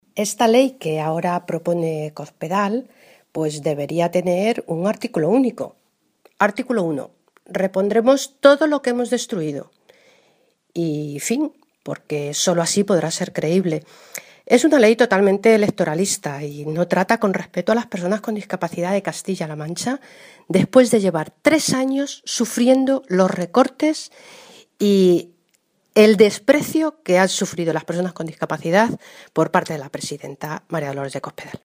La diputada nacional del PSOE, Guadalupe Martín, ha señalado hoy que el proyecto de ley de garantía de los derechos de las personas con discapacidad, que ayer aprobó el Consejo de Gobierno de Castilla-La Mancha, “llega tarde, es electoralista y supone una falta de respeto a los discapacitados de nuestra región, que han venido sufriendo los recortes de Cospedal durante todos estos años”.
Cortes de audio de la rueda de prensa